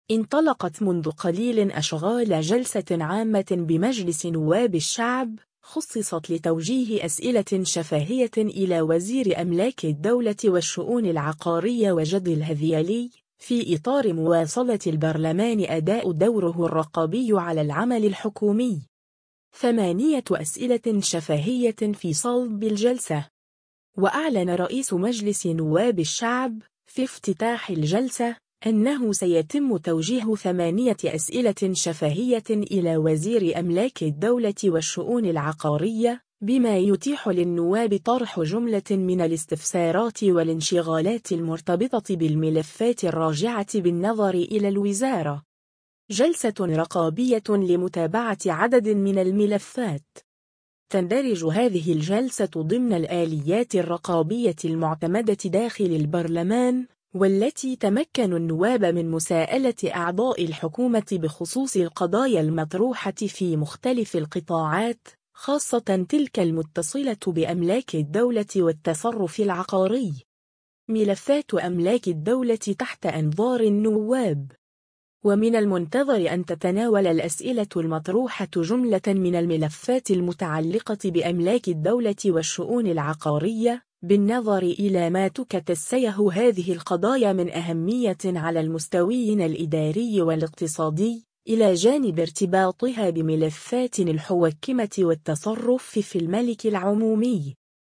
جلسة رقابية تحت قبة البرلمان: 8 أسئلة شفاهية لوزير أملاك الدولة (فيديو)
انطلقت منذ قليل أشغال جلسة عامة بمجلس نواب الشعب، خُصصت لتوجيه أسئلة شفاهية إلى وزير أملاك الدولة والشؤون العقارية وجدي الهذيلي، في إطار مواصلة البرلمان أداء دوره الرقابي على العمل الحكومي.